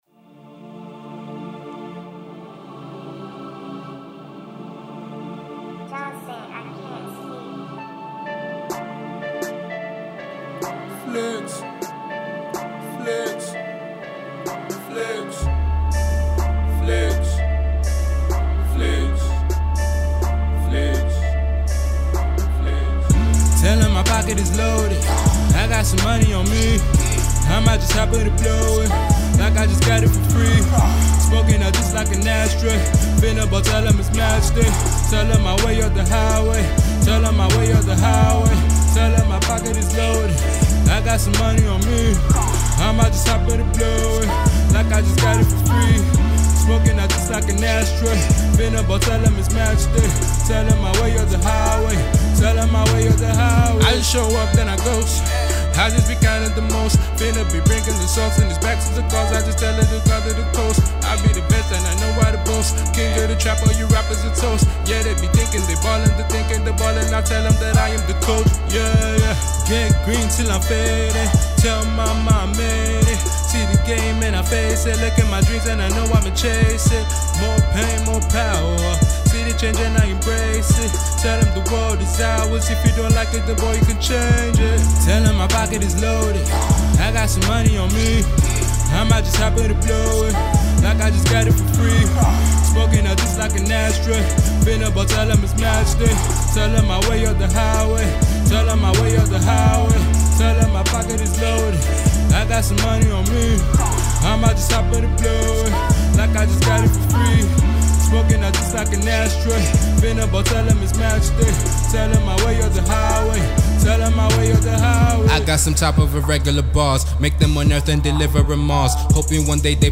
Fast-rising Hip Hop/Trap artiste
impressive trap tune